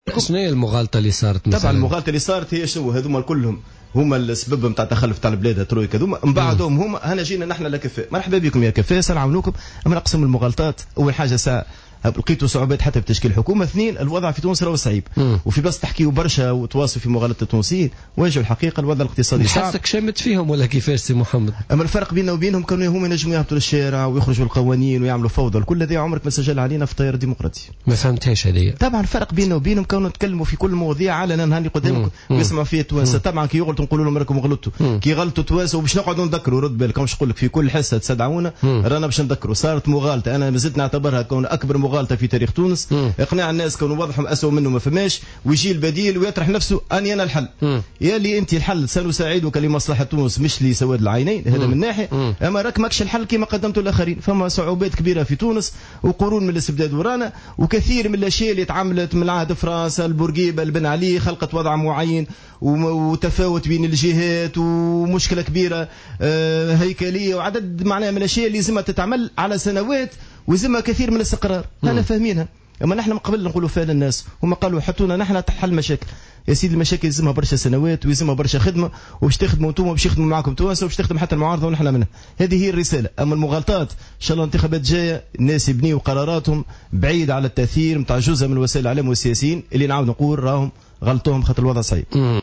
قال الأمين العام للتيار الديمقراطي، محمد عبو في تصريح للجوهرة أف أم اليوم الاربعاء إن حركة نداء تونس نفذت أكبر عملية مغالطة في تاريخ تونس حينما كانت تهاجم الترويكا الحاكمة سابقا وتصفها بسبب تخلف البلاد وحينما أظهرت نفسها على أنها البديل القادر على تخليص تونس من وضعها الصعب بفضل كفاءاتها.